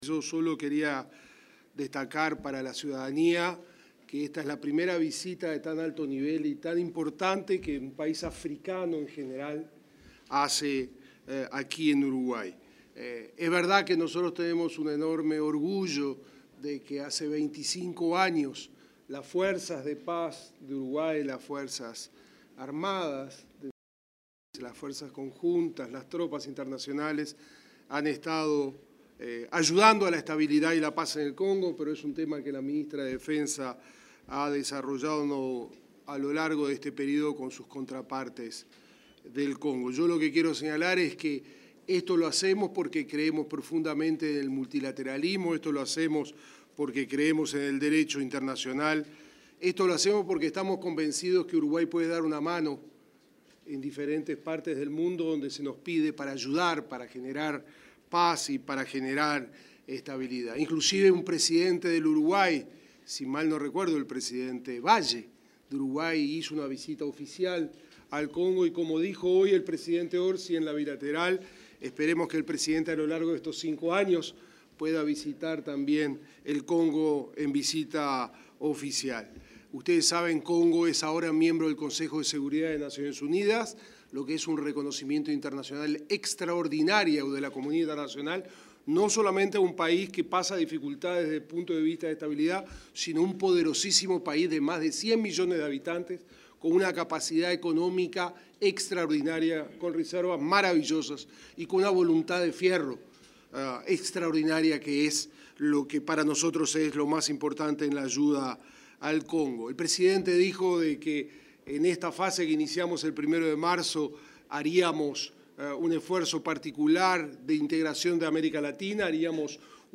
Palabras del ministro de Relaciones Exteriores, Mario Lubetkin